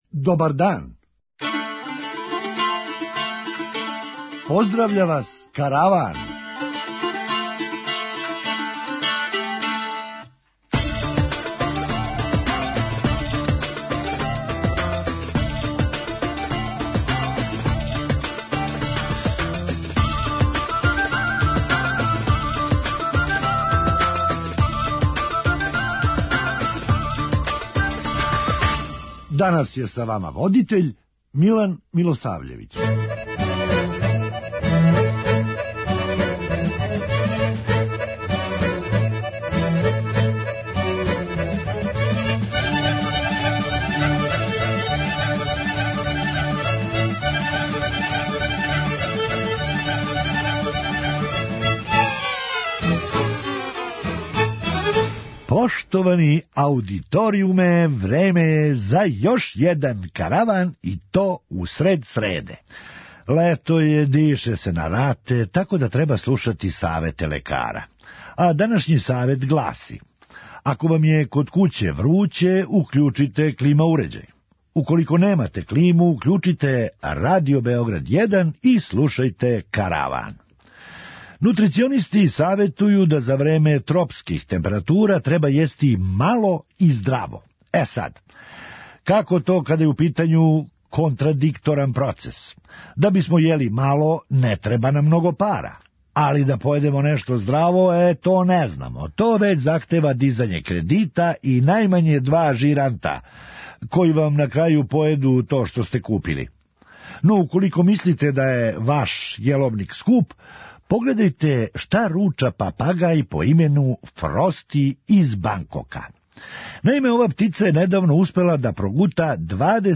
Хумористичка емисија
Власник каже да није планирао да је прода али нужда закон мења. преузми : 9.04 MB Караван Autor: Забавна редакција Радио Бeограда 1 Караван се креће ка својој дестинацији већ више од 50 година, увек добро натоварен актуелним хумором и изворним народним песмама.